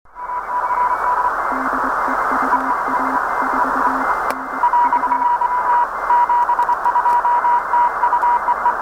Это в поле, точнее в лесу, приём на Р-143.